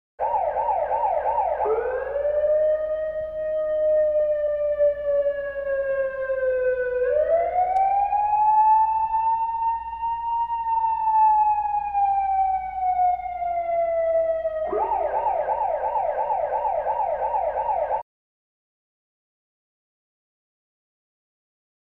На этой странице собраны звуки городского шума: гул машин, разговоры прохожих, сигналы светофоров и другие атмосферные звуки улиц.